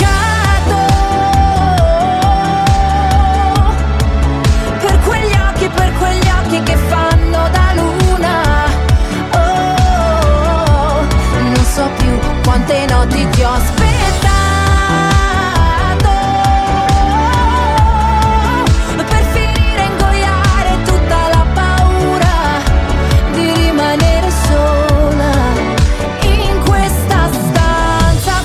Genere: italiana,sanremo2025,pop.ballads,rap,hit